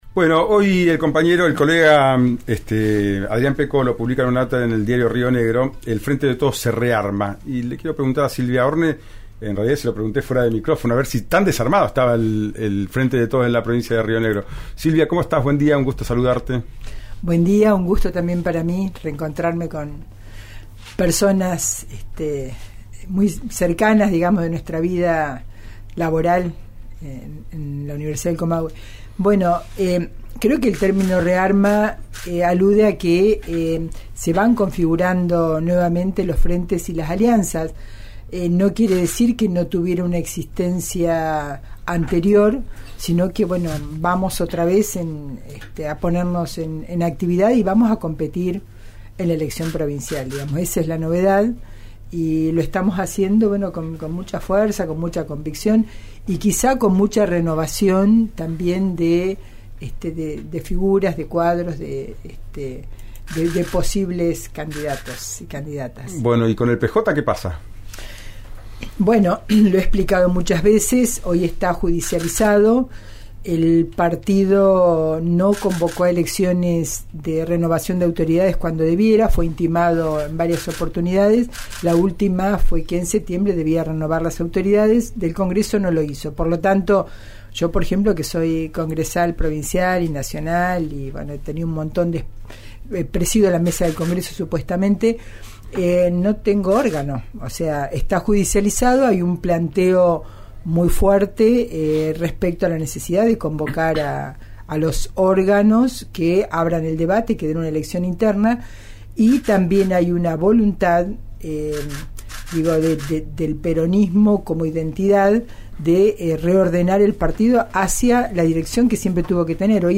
Escuchá a Silvia Horne en «Ya es tiempo» por RÍO NEGRO RADIO: